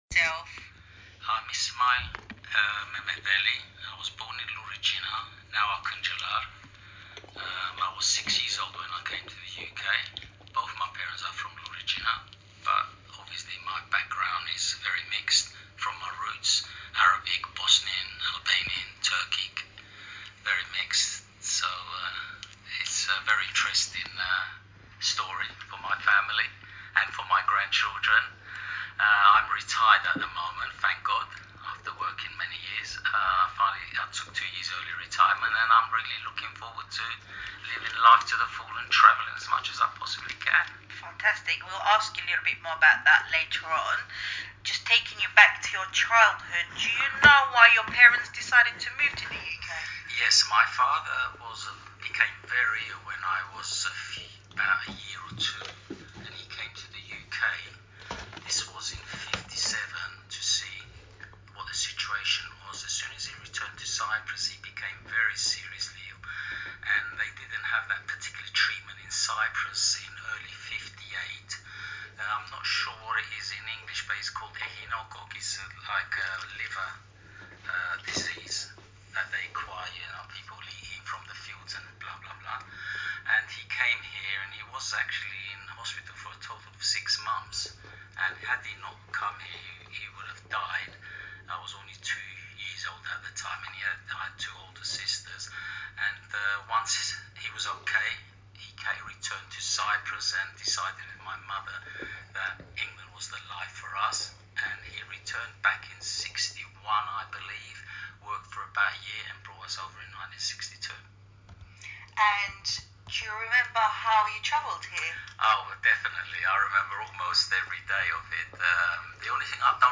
Throughout 2022 interviews were conducted with members of the early Turkish Cypriot migration to Enfield.